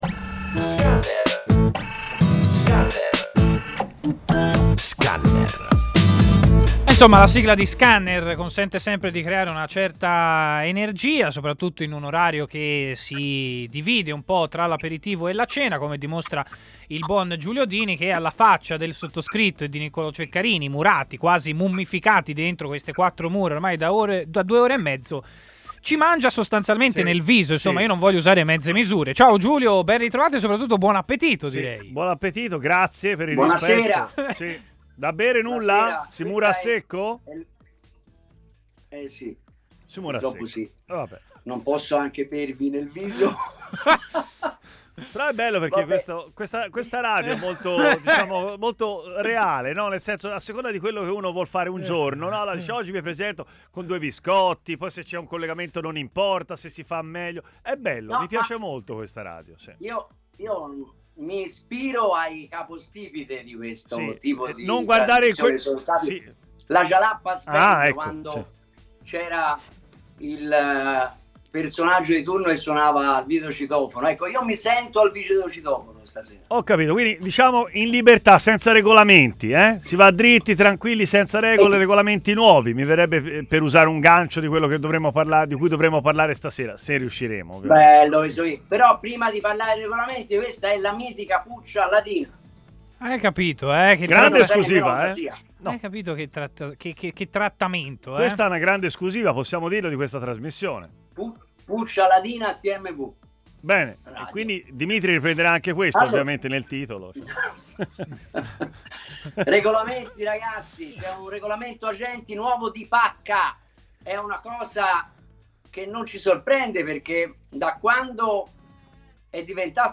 trasmissione di TMW Radio